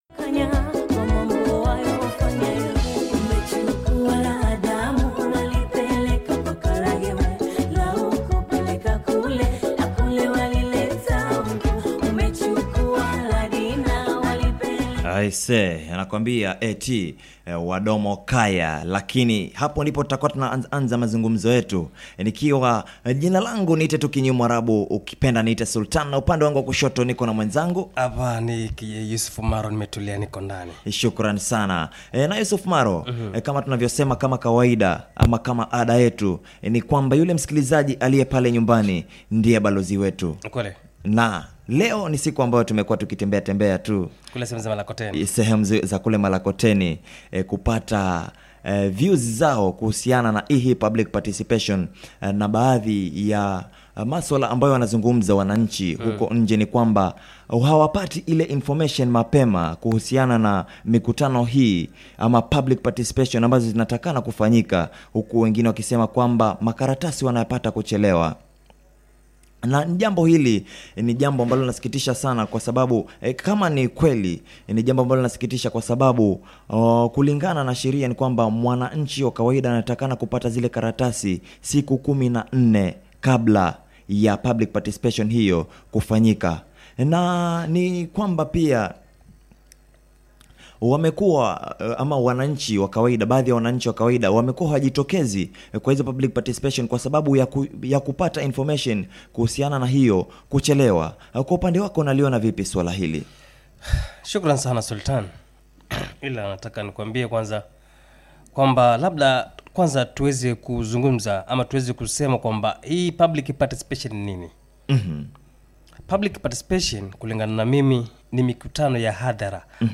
The talk show is produced by Koch Fm and is on public participation especially the role of the citizens, the county leadership and other important stakeholders. The talk show is part of ‘our county our responsibility project’ which was undertaken to empower the local population to actively participate in all democratic processes at the county level.